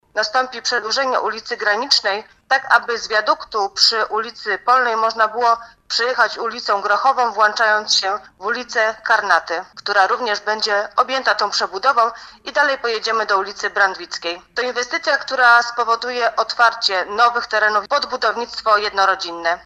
Zadanie to pozwoli na otwarcie nowych terenów pod budownictwo jednorodzinne w Stalowej Woli. Informowała o tym wiceprezydent miasta Renata Knap: